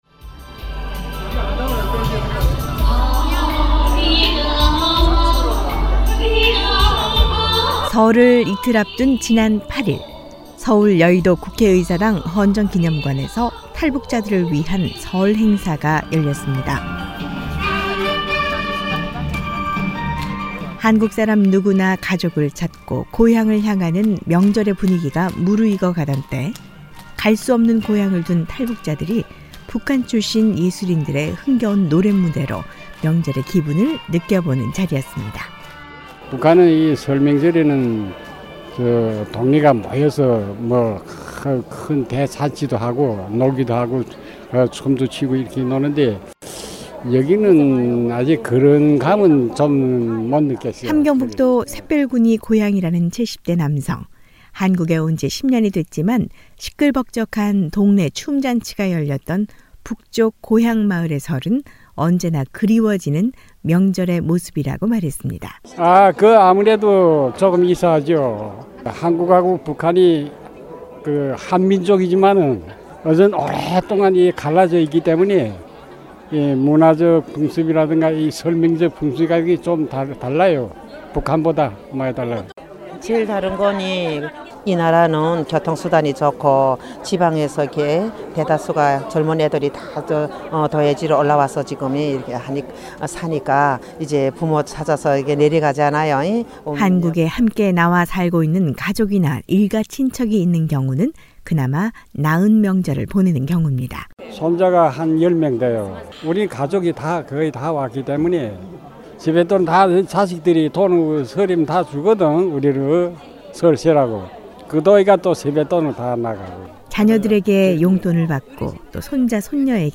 오늘은 한국에서 ‘명절-설’을 맞은 탈북자들의 목소리를 모아봤습니다. 북한이나 한국이나 ‘설’은 똑같은 민족의 명절인데, 설을 지내는 방법은 너무나 다른 모습이고, 명절에 고향을 생각하는 탈북자들의 마음은 더 깊어지기 마련입니다.